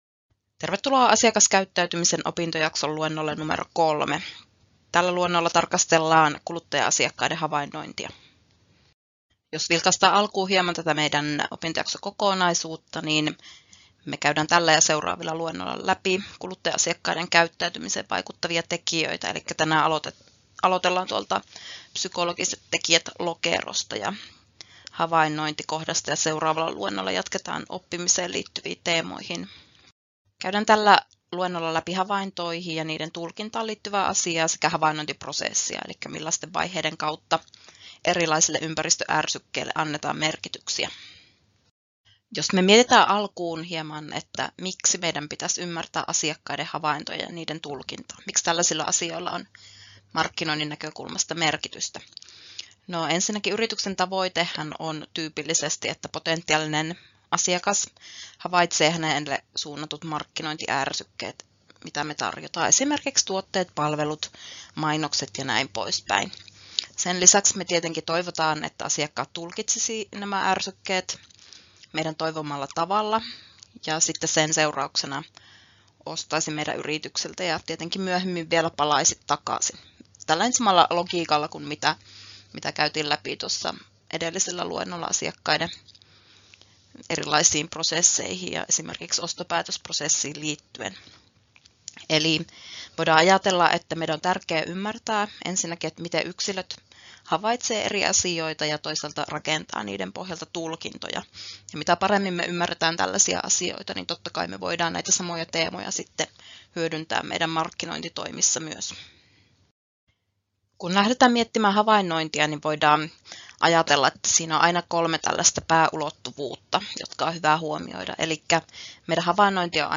Luento 3: Psykologiset tekijät (havainnointi) — Moniviestin